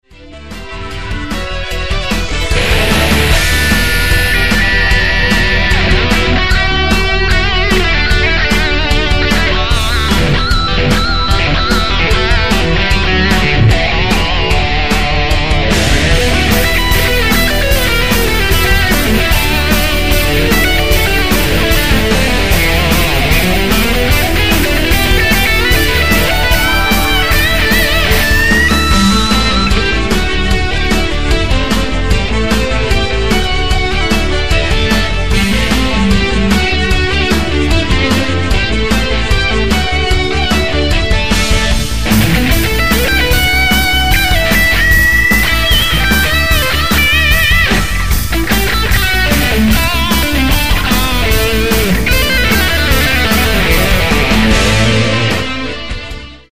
Fender Stratocaster
effecten: octaver uit de Digitech XP100
versterkers: Koch Twintone en de Fender Twin Reverb
Mijn gitaargeluid is vies en schel.
Dit geeft een ontiegelijk snerpend en agresief schel geluid.
Zonder de effecten klinkt het vooral bluesy en met effecten totaal anders namelijk vies en schel.